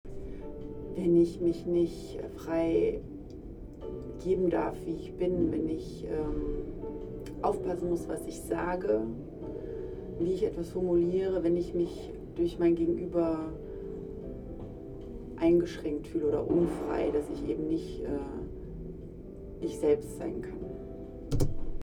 FONA Forum @ Berlin, Futurum
Standort war das Berlin, Futurum. Der Anlass war FONA Forum